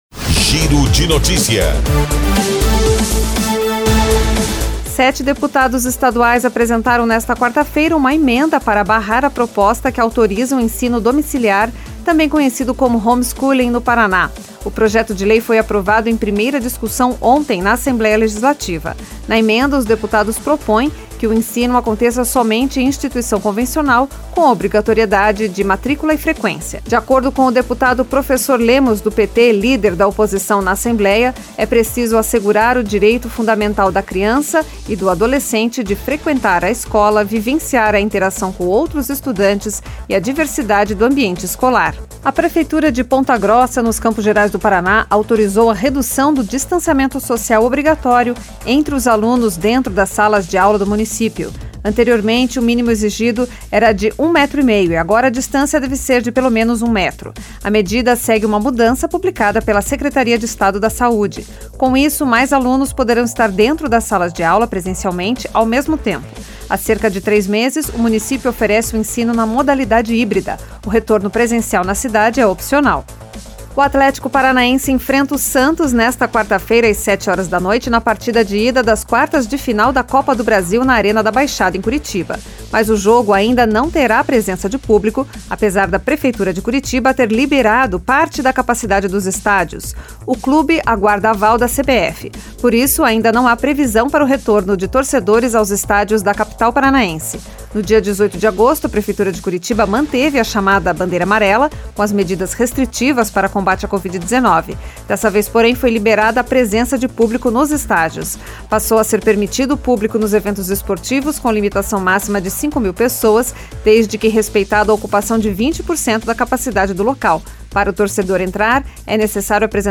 Giro de Notícias Tarde